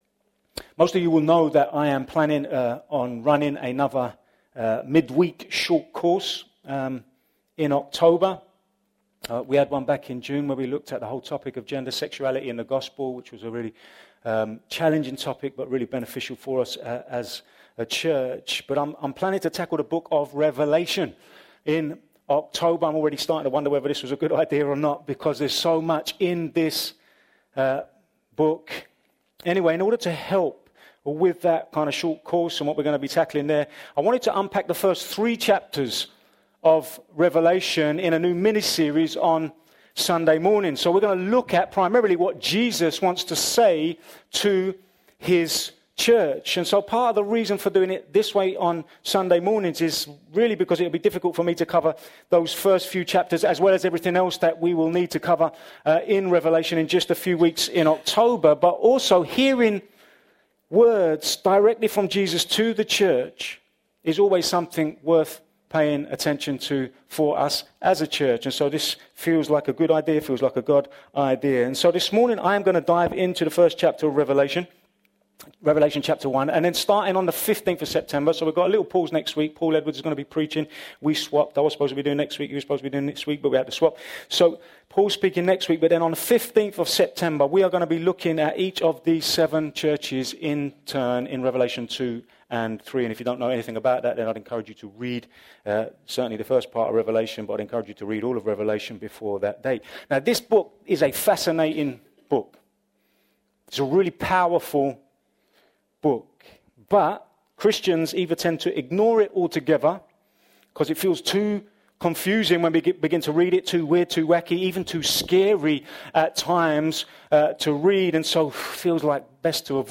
A message from the series "Sunday Morning." As John opens his apocalyptic, prophetic, letter to the seven churches he gives us a powerful vision of Jesus Christ which is intended to lay a foundation for teh whole book.